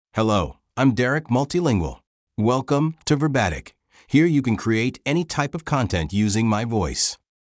Derek Multilingual — Male English (United States) AI Voice | TTS, Voice Cloning & Video | Verbatik AI
Derek Multilingual is a male AI voice for English (United States).
Voice sample
Listen to Derek Multilingual's male English voice.
Male
Derek Multilingual delivers clear pronunciation with authentic United States English intonation, making your content sound professionally produced.